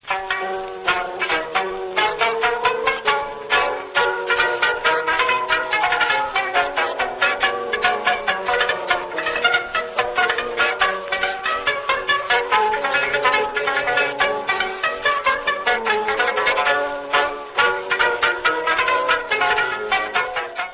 Wax cylinder excerpts . . .